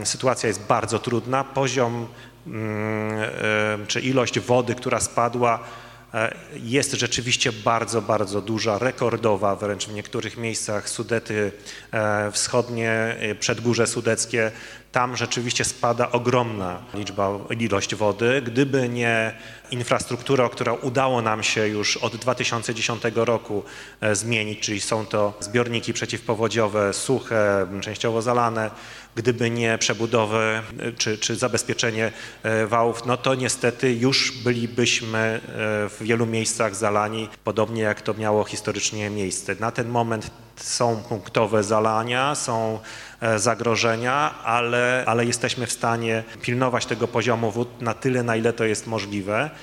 Wojewoda dolnośląski Maciej Awiżeń przedstawił jak wyglądała sytuacja w sobotę do godz. 20:30.